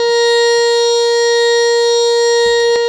52-key17-harm-a#4.wav